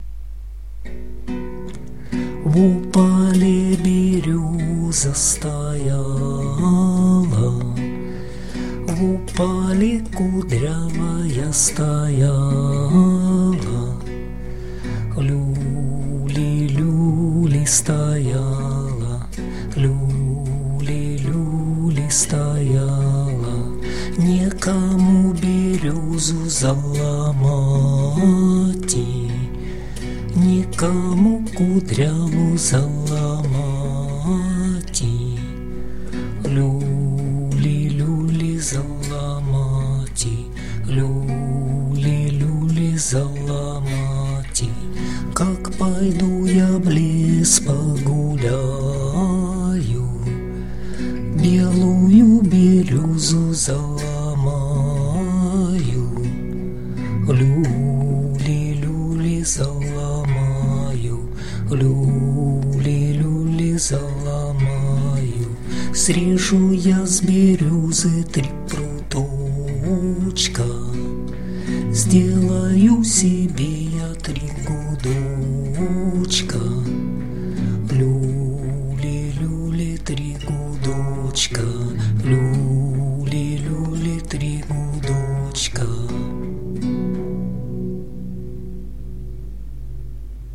../icons/vosaduli.jpg   Русская народная песня